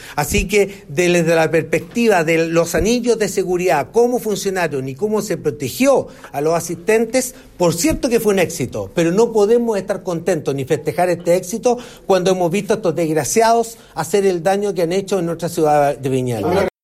El intendente de la región de Valparaíso, Jorge Martínez, entregó declaraciones poco antes de la medianoche. Dijo que los anillos de seguridad funcionaron para lo que fue el ingreso del público al festival y calificó en duros términos a quienes protagonizaron estos hechos de violencia.